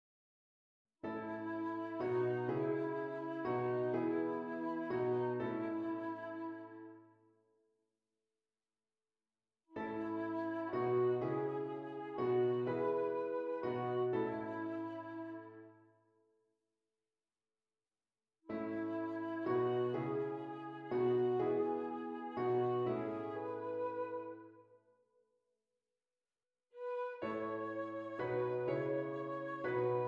Flute Solo with Piano Accompaniment
C Sharp Minor
Lento